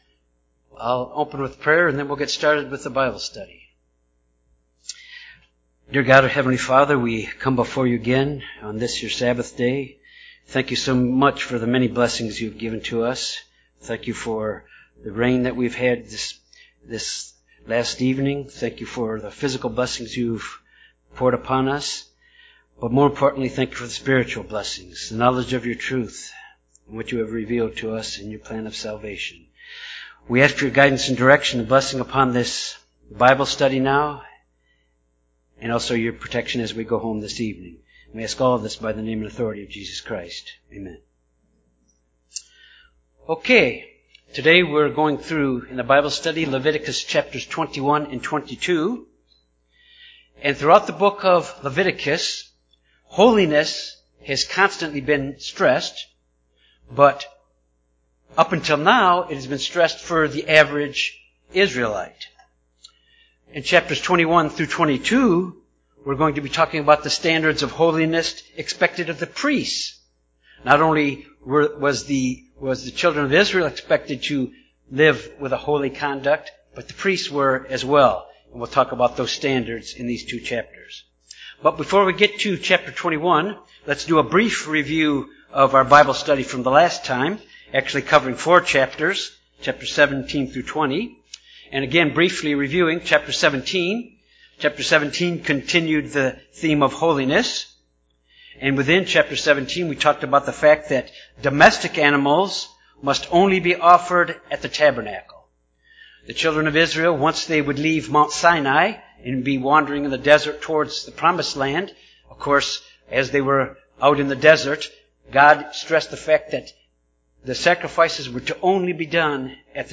This Bible study covers Leviticus 21-22. In this study, laws regulating the priesthood and the high priest and the selection of the animals to be used in sacrifices are examined
Given in Little Rock, AR